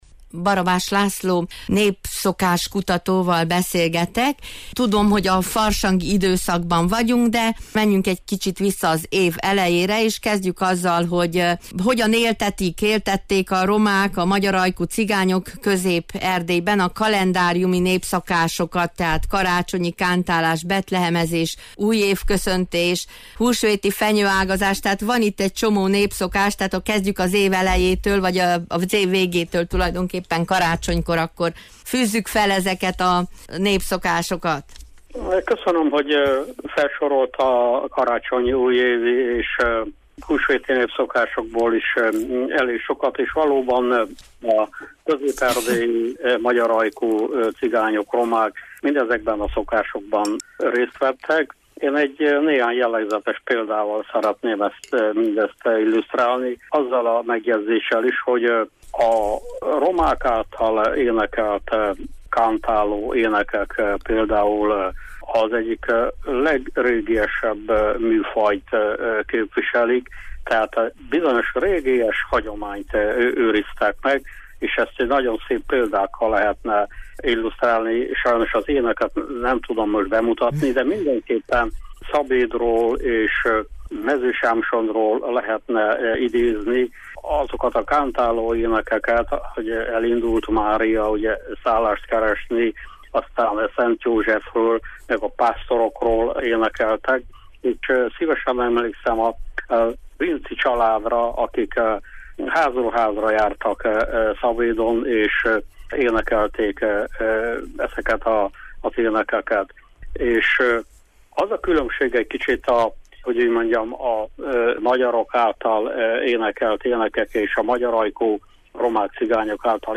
A beszélgetés február 7-ei műsorunkban hangzott el, és itt visszah